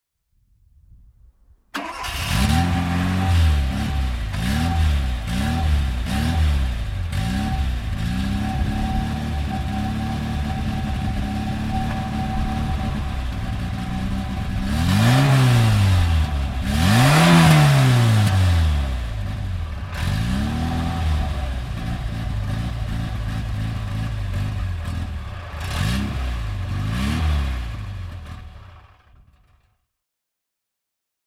Fiat X 1/9 (1979) - Starten und Leerlauf